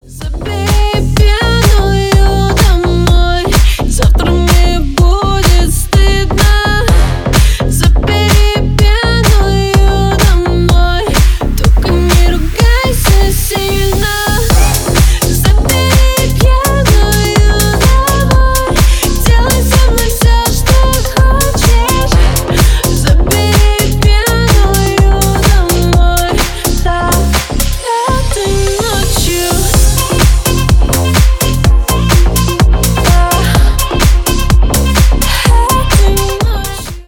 • Качество: 320 kbps, Stereo
Ремикс
клубные
громкие